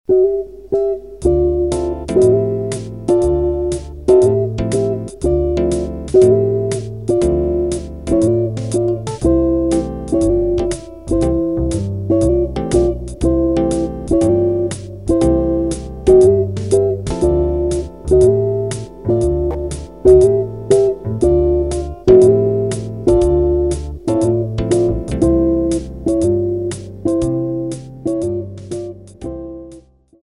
Jazz Audios